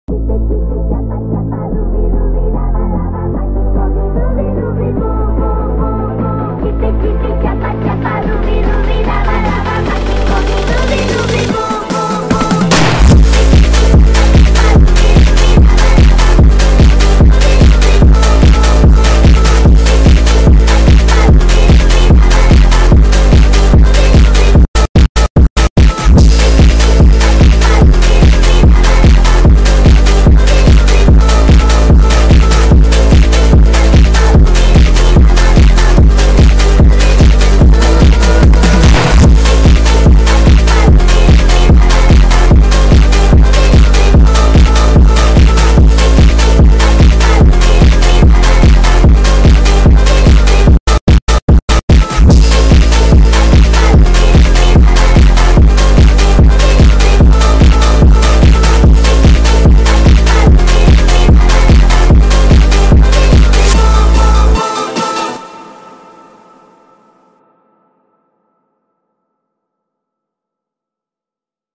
фонк ремикс мем с котом